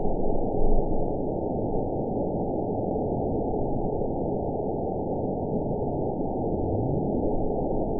event 920061 date 02/21/24 time 02:40:35 GMT (1 year, 3 months ago) score 9.08 location TSS-AB09 detected by nrw target species NRW annotations +NRW Spectrogram: Frequency (kHz) vs. Time (s) audio not available .wav